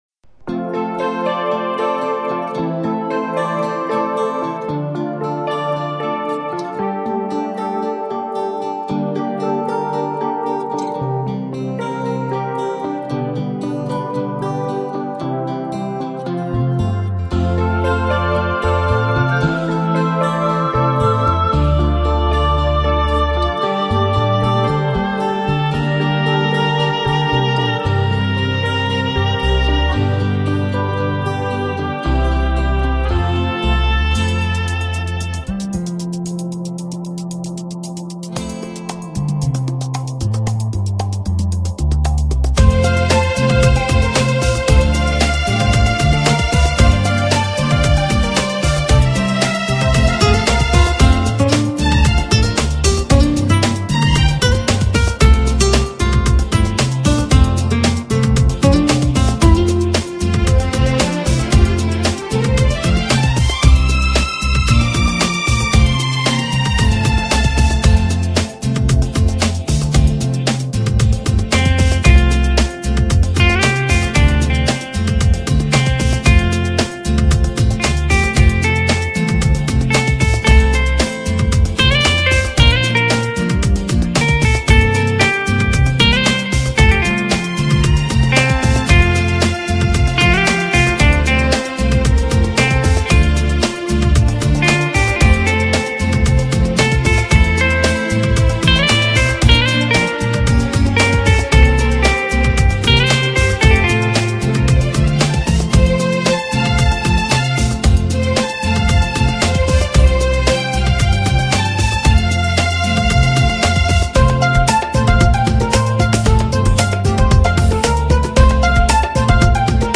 HomeMp3 Audio Songs > Instrumental Songs > Old Bollywood